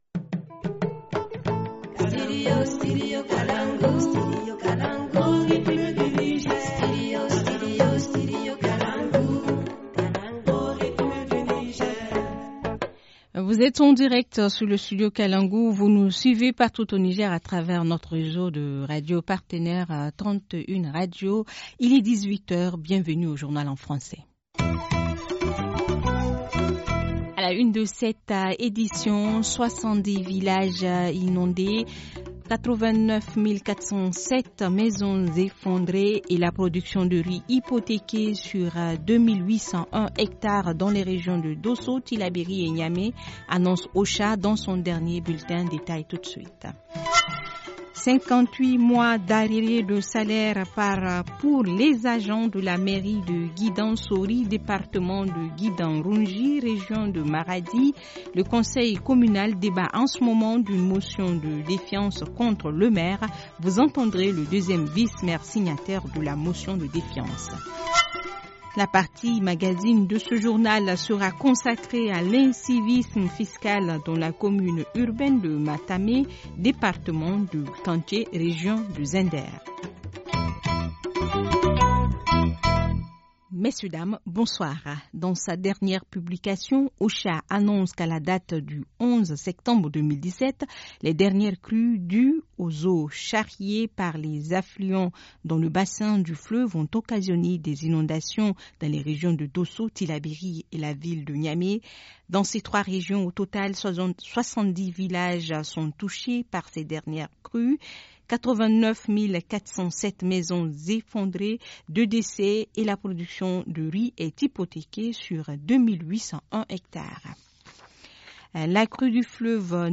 Journal du 14 septembre 2017 - Studio Kalangou - Au rythme du Niger